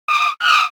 propellersscreetch.ogg